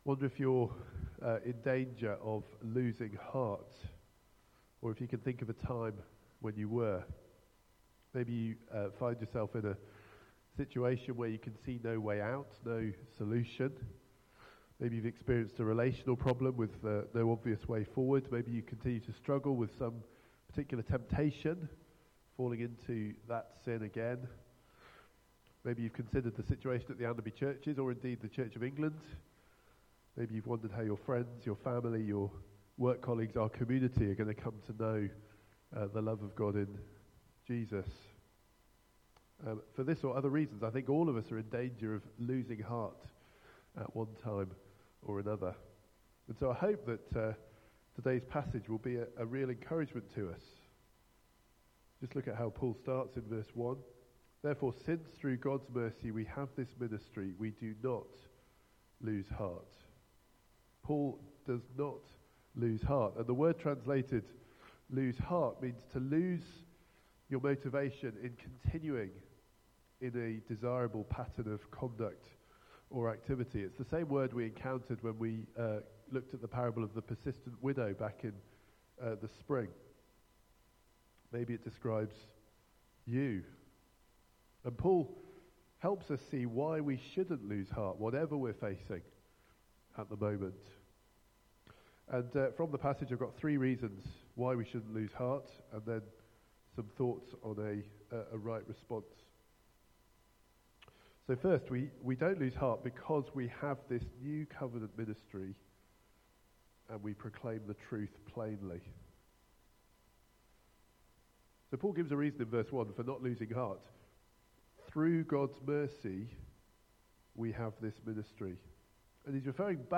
Media Library The Sunday Sermons are generally recorded each week at St Mark's Community Church.
Series: Strength in weakness Theme: How not to lose heart Sermon